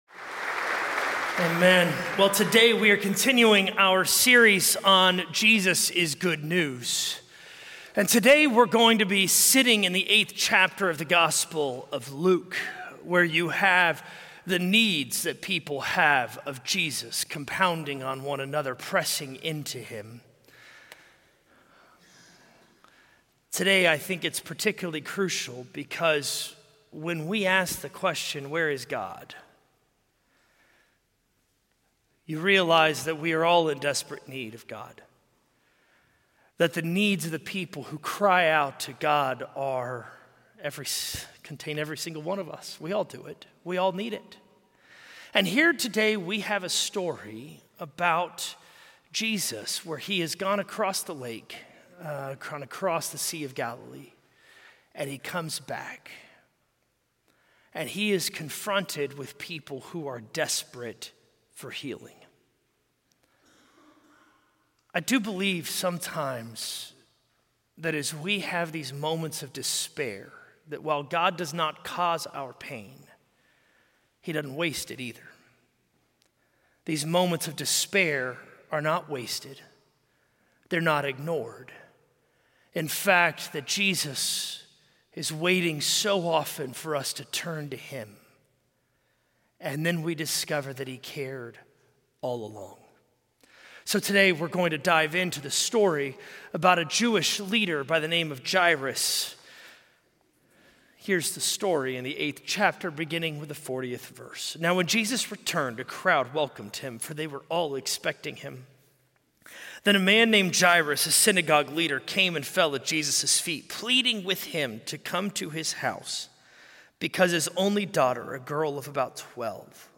A message from the series "Jesus Is Good News."